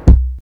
WU_BD_069.wav